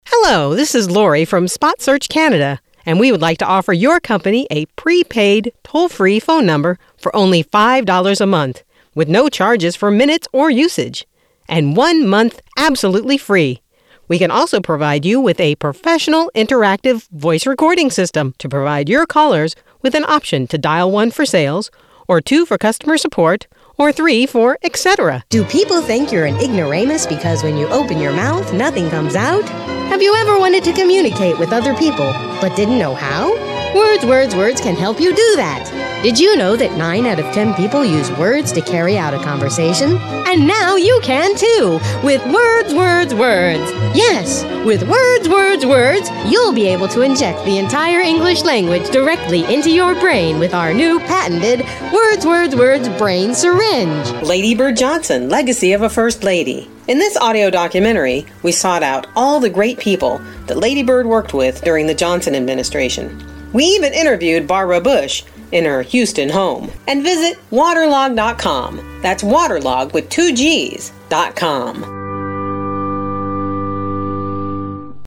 Commercials
Middle Aged